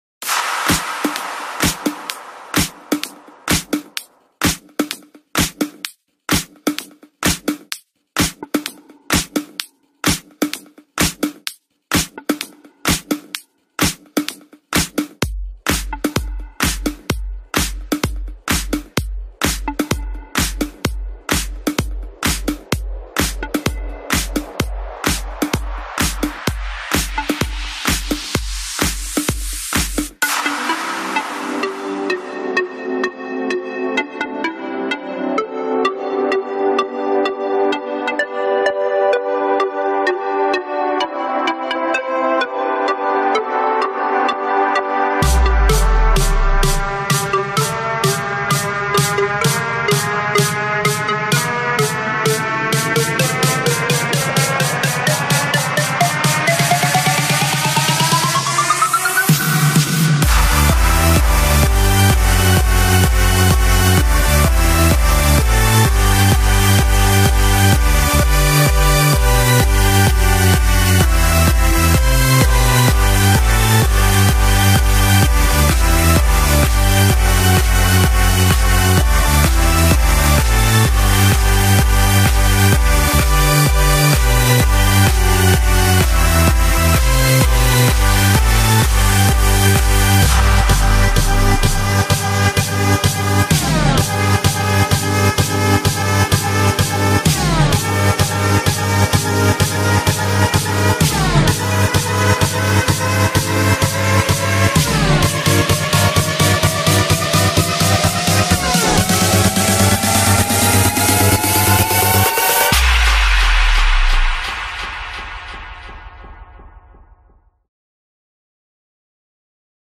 بیس دار تند